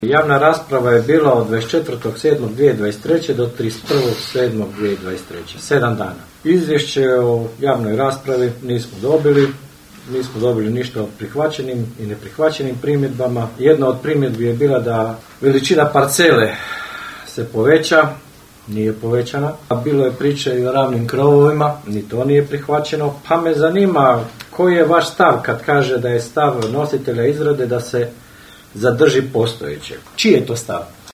Sa sedam glasova za vijećnika vladajuće većine i šest glasova protiv oporbenih vijećnika na sinoćnjoj su sjednici Općinskog vijeća Raše prihvaćene IV. Izmjene i dopune Prostornog plana uređenja Općine Raša.